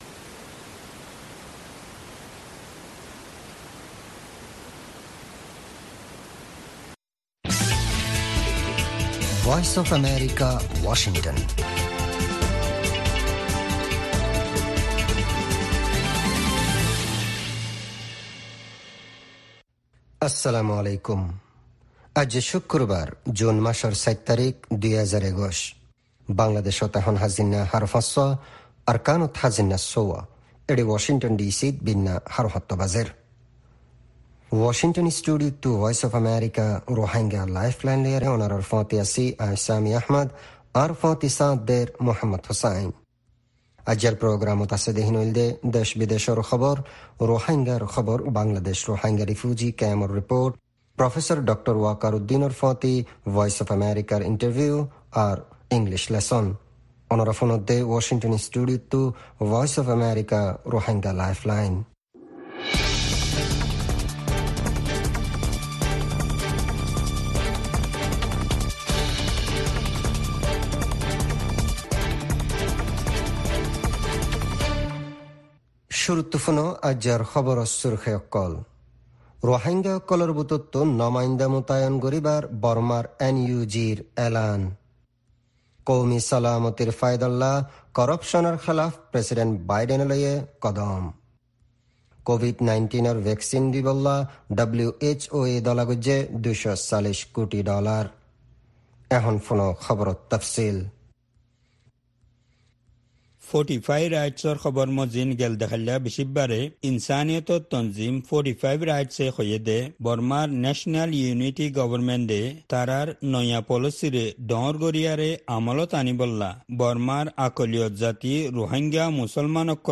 Narrative report